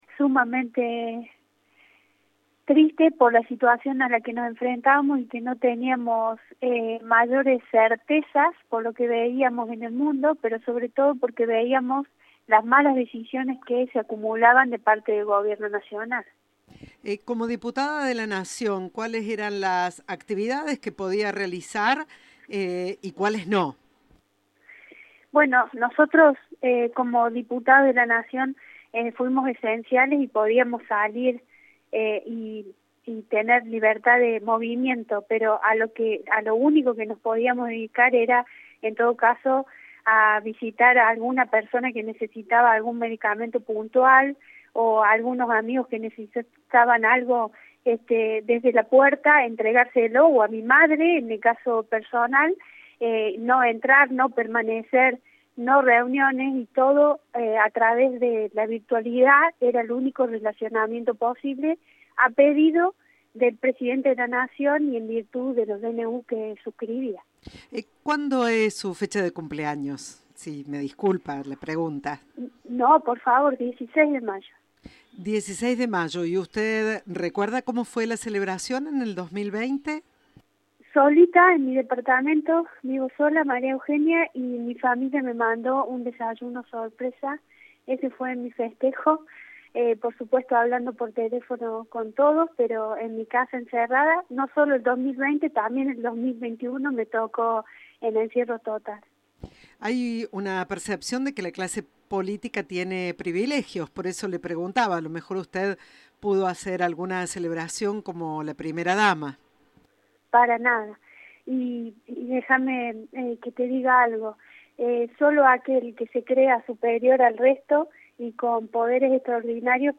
En diálogo con Cadena 3, El Sukaria opinó este jueves que el jefe de Estado se burló de toda la sociedad y, especialmente, de las víctimas de la pandemia de coronavirus.